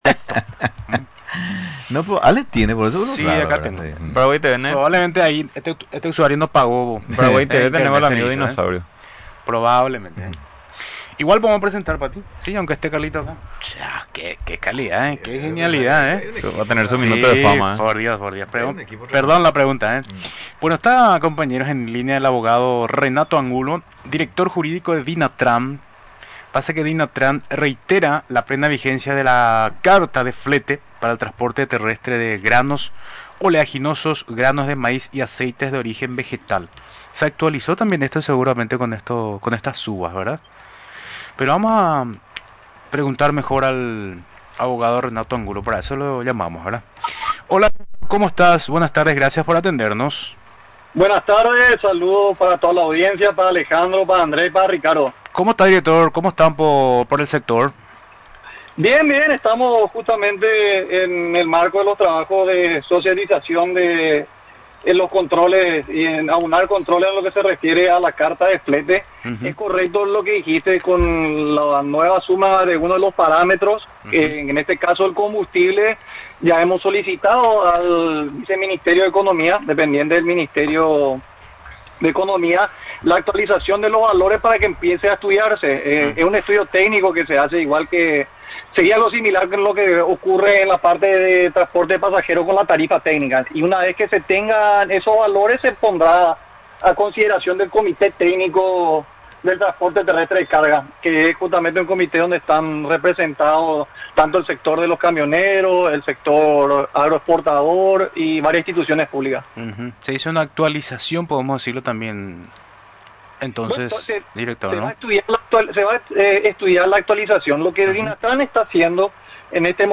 Durante entrevista en Radio Nacional del Paraguay, explicó todo los detalles sobre la plena vigencia de esta normativa.